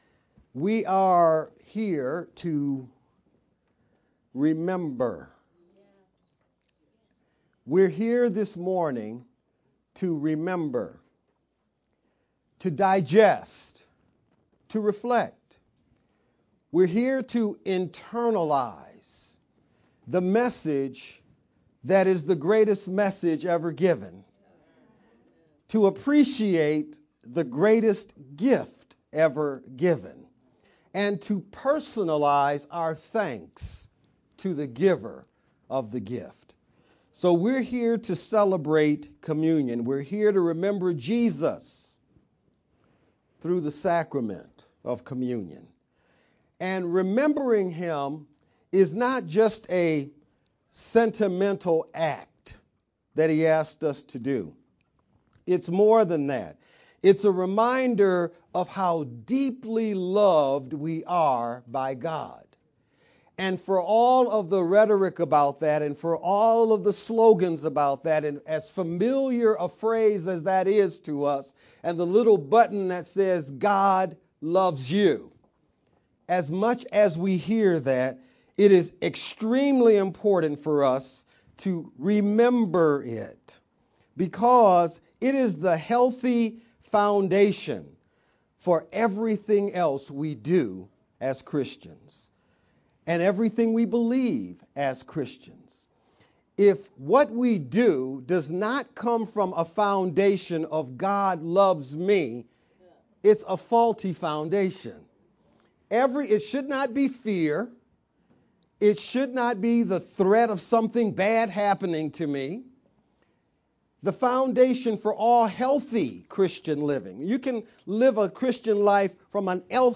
Jan-28th-VBCC-Sermon-only_Converted-CD.mp3